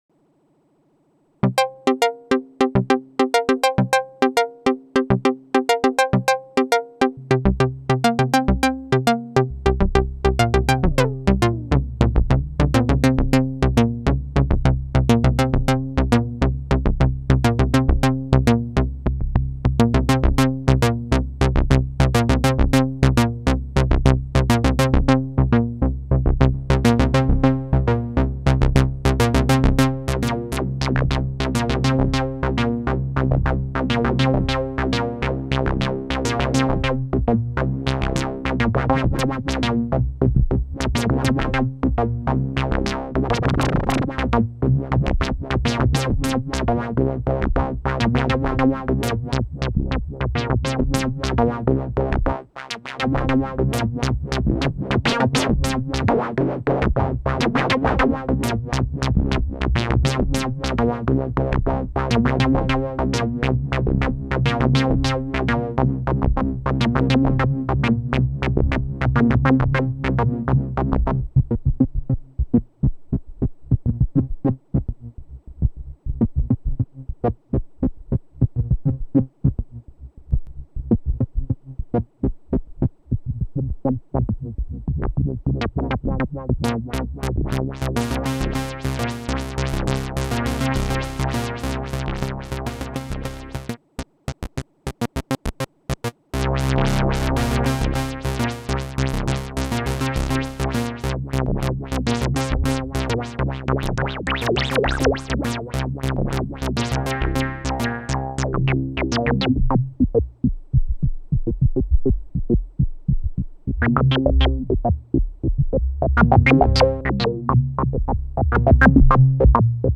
SOUND everything classic: pads, basses and sweeps
Quick & Dirty Audio Demo
Audio Demo Jp08
boutique_jp08_quickdemo.mp3